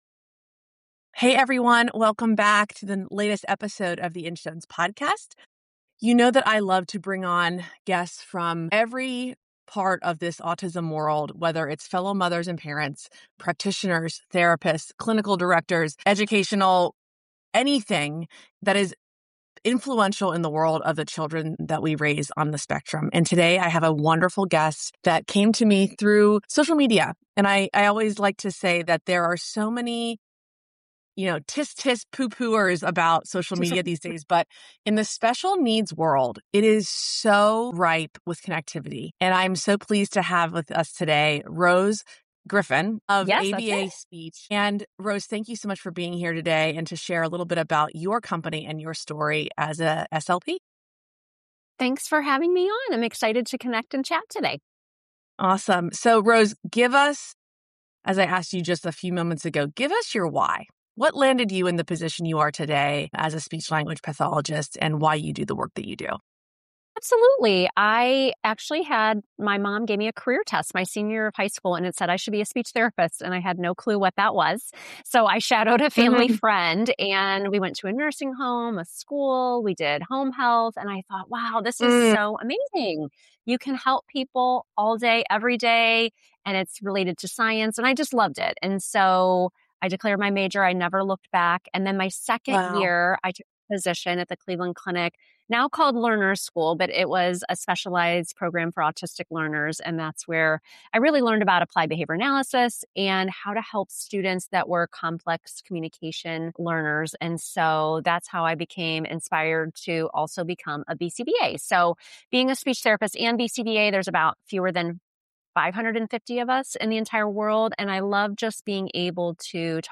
Communication is not an accessory skill in autism support, it is foundational. In this conversation